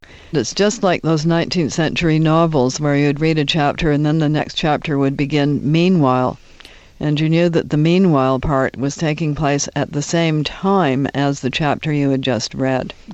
The speaker has just made it up and gave it the correct accentual pattern.